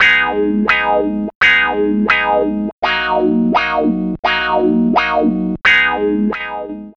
Index of /90_sSampleCDs/Zero-G - Total Drum Bass/Instruments - 1/track35 (Guitars)
04 Clean 170 G#min.wav